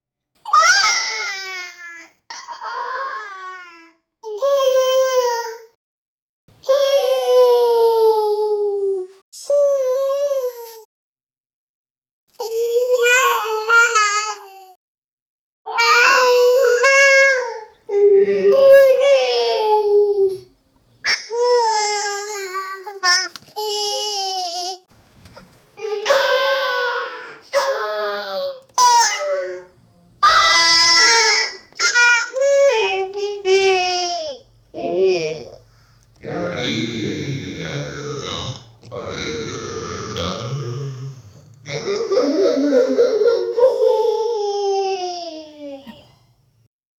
House King Kong Roar Sounds and With Godzilla Roar sound With Window Breaking Glass Sounds With Baby Crying Voice With Singing Man And With Earthquake Sound And With Kids Talking Says Company Name And With Woman Talks With Advertising Voices (2023)
house-king-kong-roar-soun-yhfitimm.wav